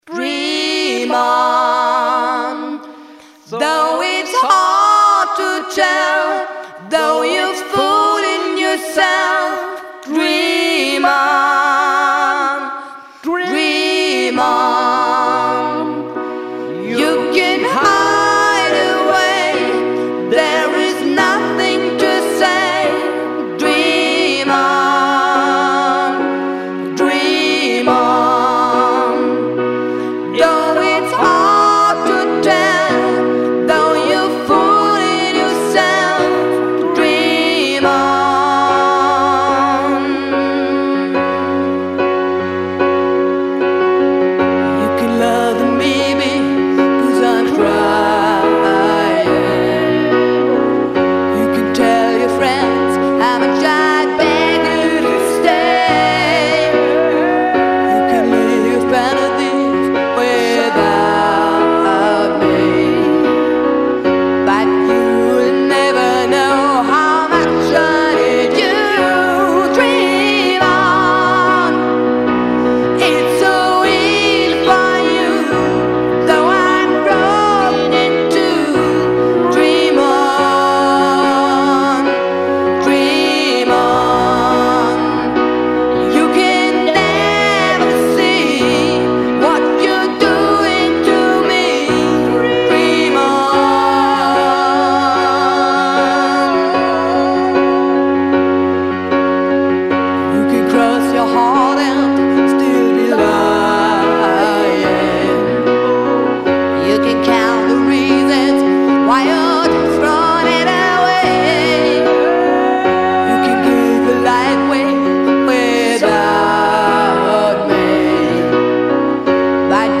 Tipp:  Mit Kopfhörern besseres Klangbild ( Stereoeffekte )
Lead- und Backgroundvocal & Klavier
Gitarre